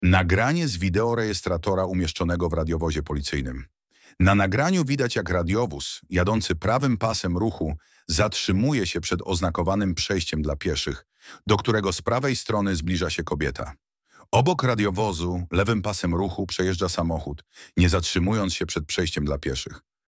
Audiodeskrypcja do nagrania - plik mp3